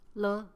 le5.mp3